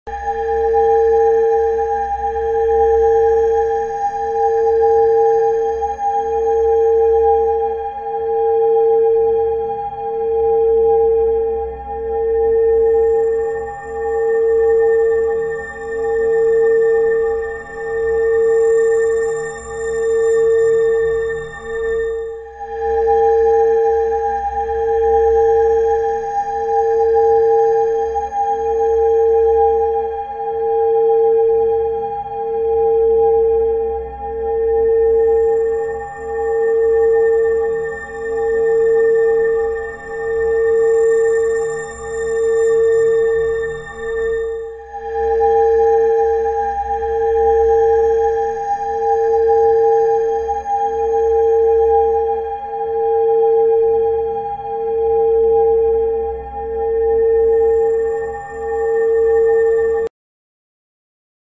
Binaural sound frequency 745hz sound effects free download